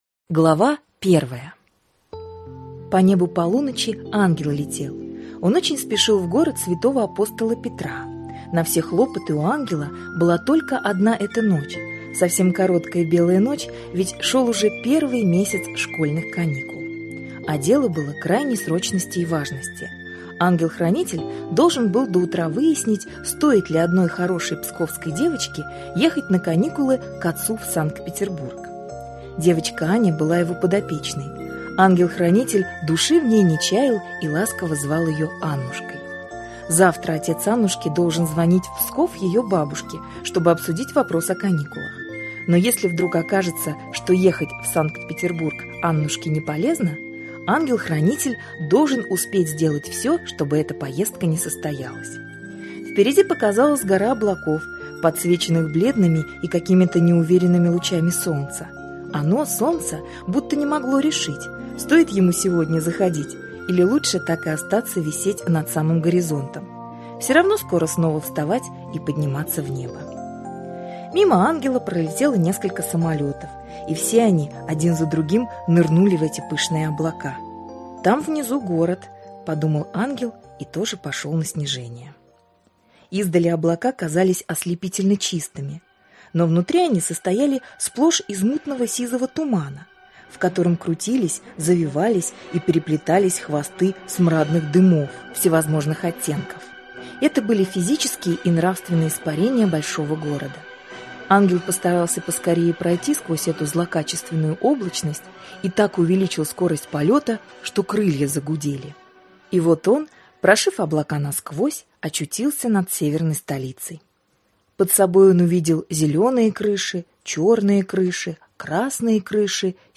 Аудиокнига Юлианна, или Игра в киднеппинг | Библиотека аудиокниг